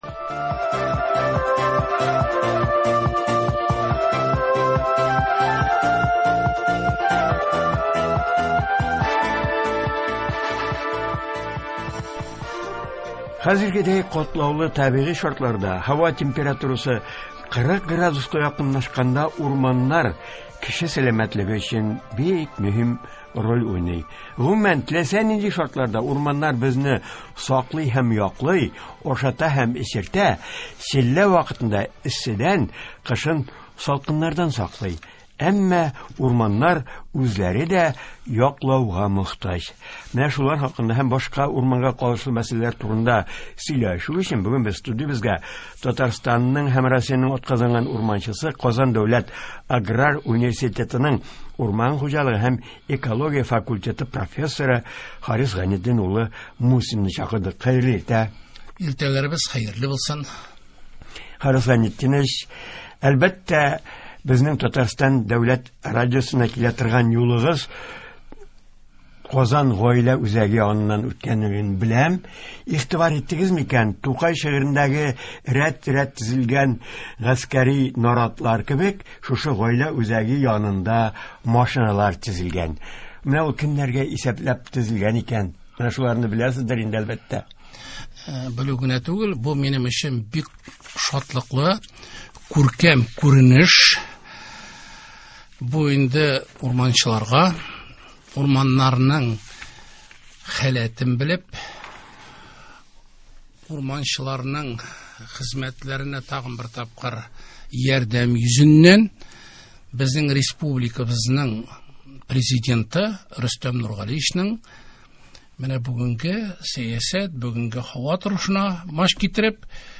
Туры эфир. 15 июль.